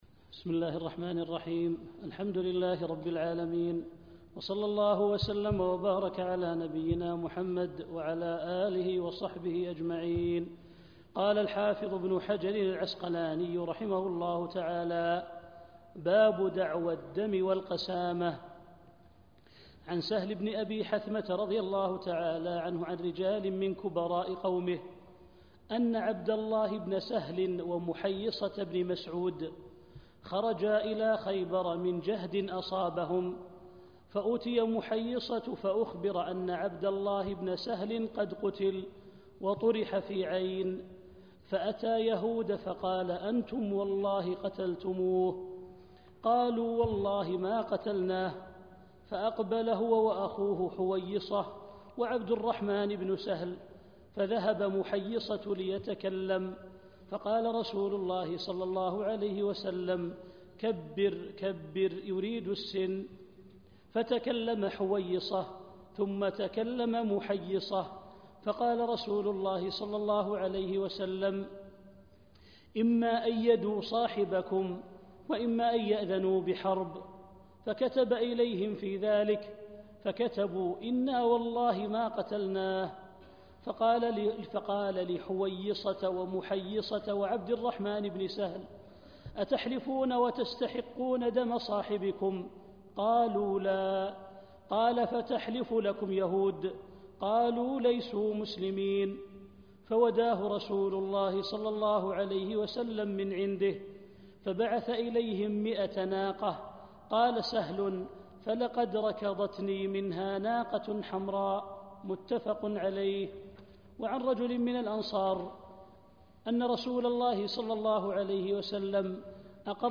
عنوان المادة الدرس (4) كتاب الجنايا من بلوغ المرام تاريخ التحميل الثلاثاء 30 يناير 2024 مـ حجم المادة 29.72 ميجا بايت عدد الزيارات 241 زيارة عدد مرات الحفظ 102 مرة إستماع المادة حفظ المادة اضف تعليقك أرسل لصديق